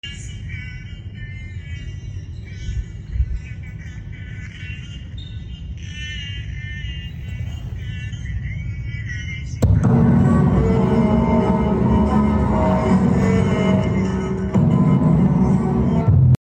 Mp3 Sound Effect Try it on a Trash! 8D sound!